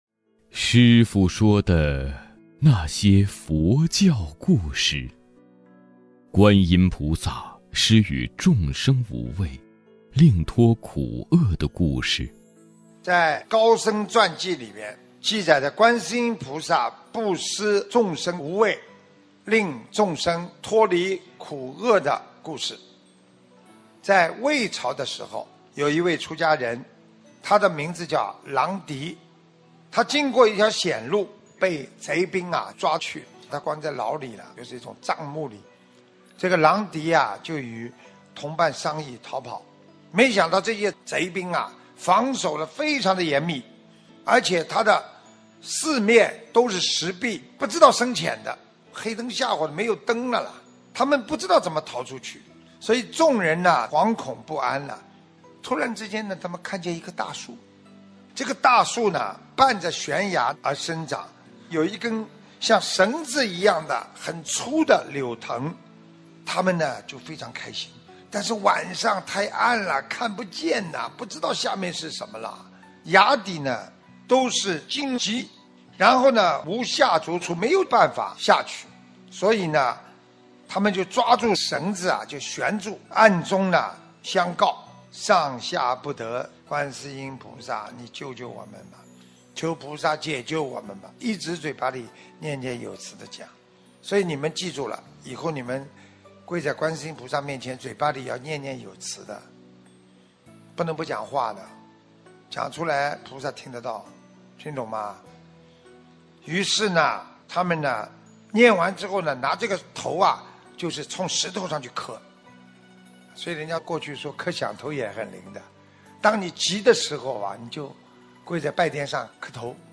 【师父原音版视频【有声大字幕【师父说的那些佛教故事】观音菩萨施与众生无畏，令脱苦厄的故事.mp3